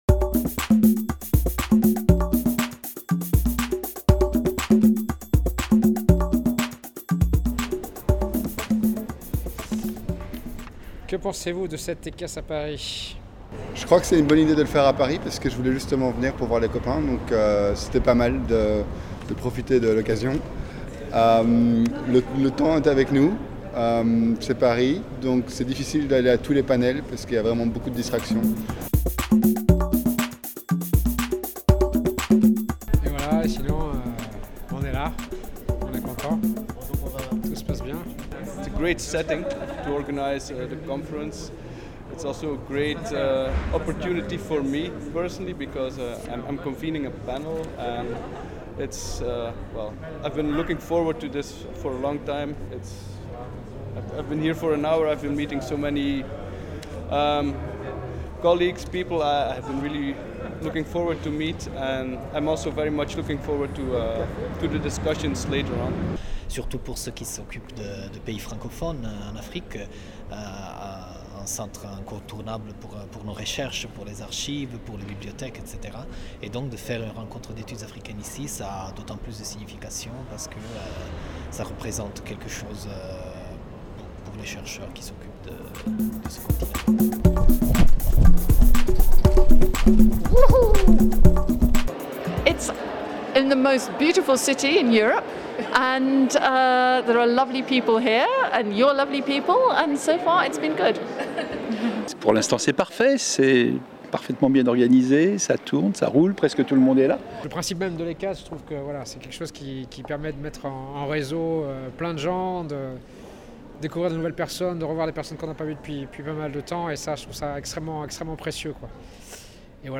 Micro-trottoir réalisé auprès des participants